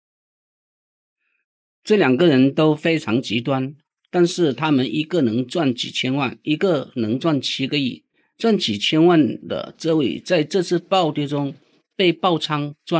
专业交易教程配音
通过专为交易教程、金融分析和投资教育设计的清晰、权威的人工智能语音，传递复杂的市场见解。
金融文本转语音
这款声音专为教学性金融内容调校，提供自然的节奏，让交易者保持专注。
我们的人工智能提供专业、类似人类的语调，传达金融教育所必需的专业知识和可靠性。
该声音通过对金融广播的严格分析而开发，保持稳定、自信的节奏，仿佛一位经验丰富的市场老手。